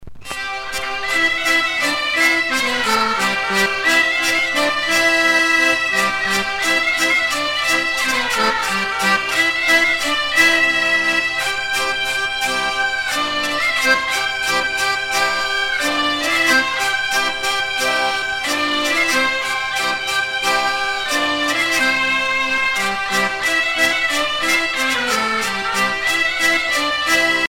Usage d'après l'analyste gestuel : danse
Genre brève
Pièce musicale éditée